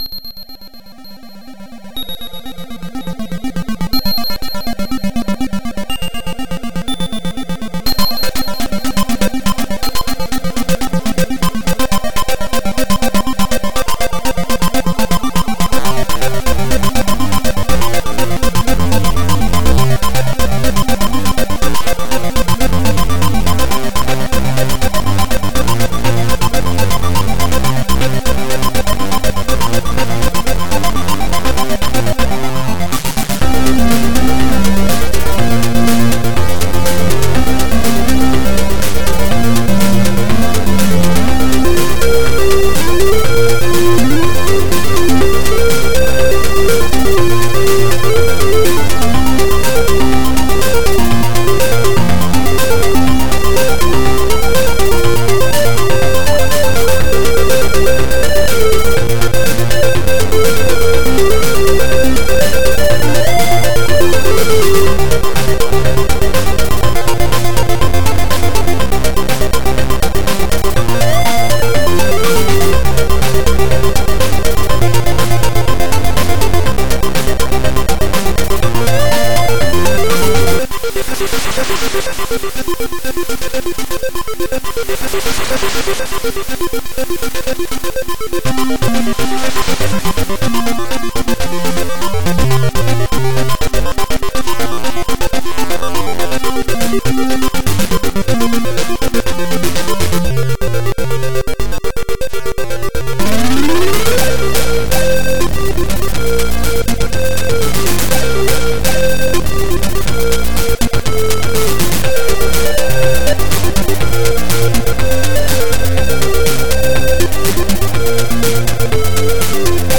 ZX Spectrum + TS
vt2 (Vortex Tracker 2.6 TS)
• Два звуковых чипа AY-3-8912 / YM2149.